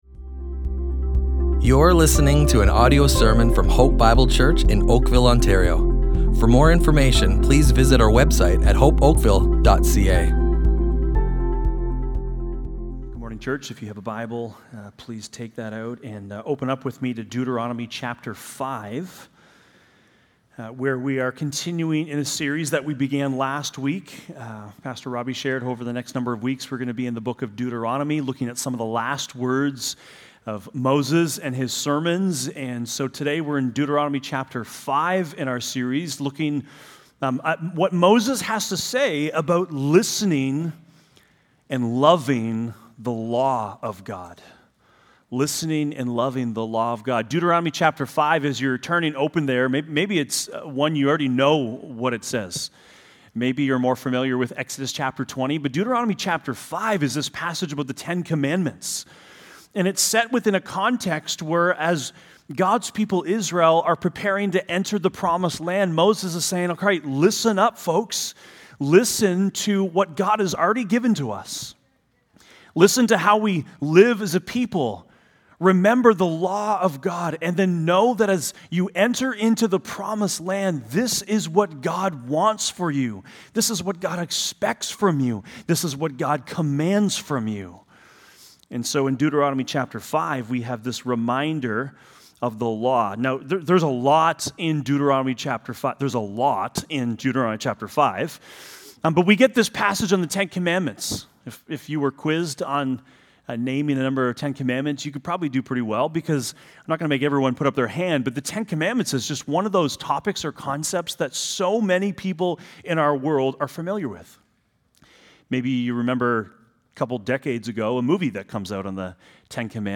Hope Bible Church Oakville Audio Sermons Listen and Love // Listen to and Love the Law of God!